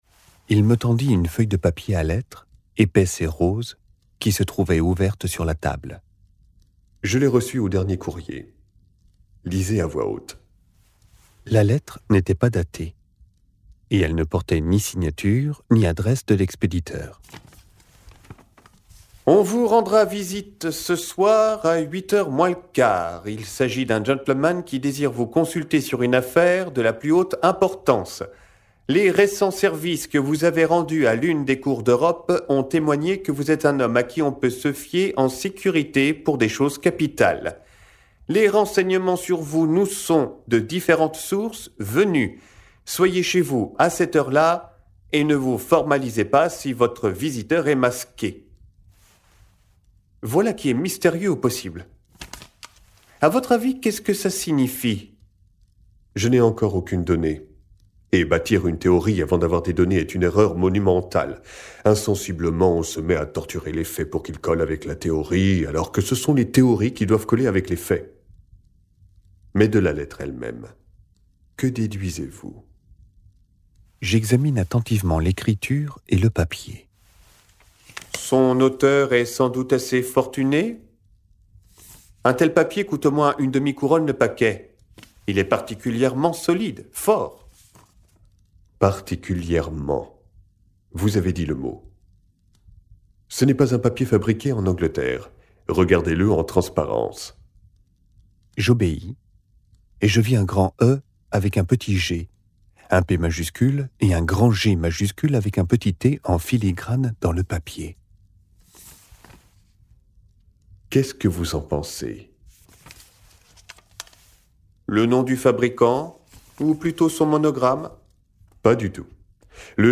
Extrait gratuit - Un scandale en Bohême de Arthur Conan Doyle